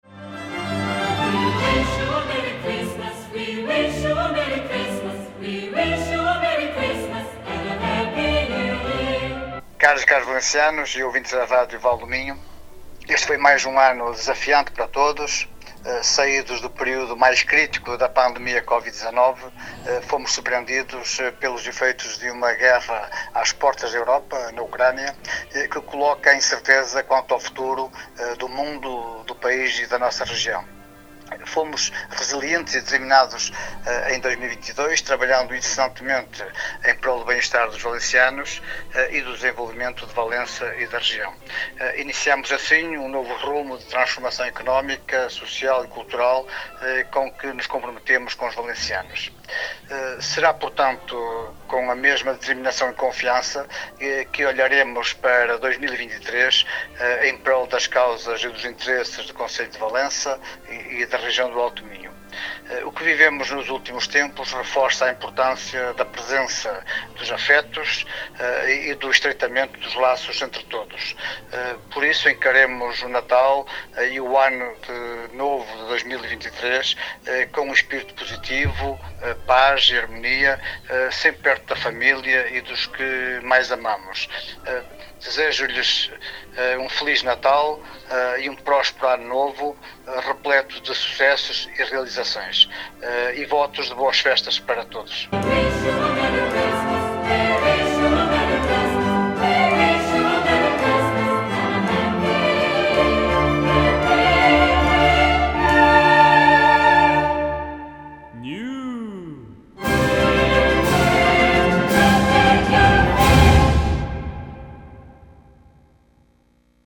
Aos microfones da Rádio Vale do Minho, o Presidente da Câmara de Valença, José Manuel Carpinteira, deixou uma mensagem de Natal à população.